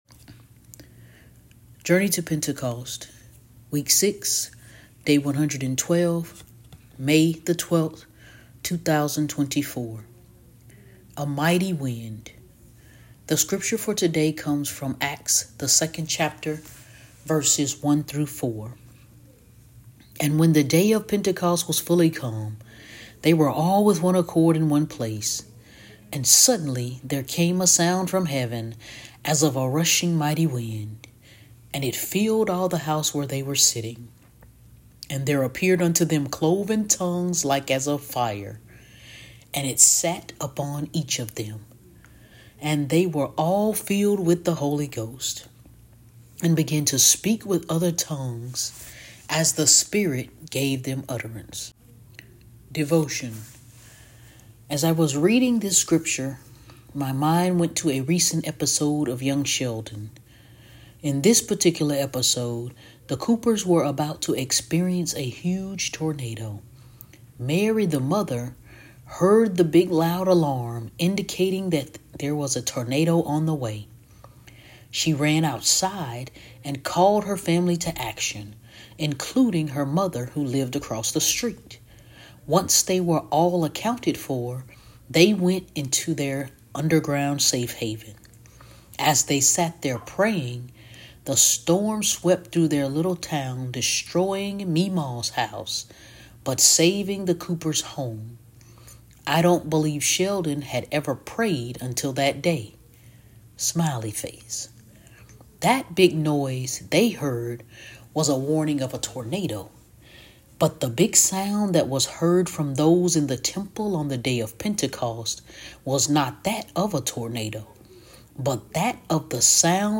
Narrator: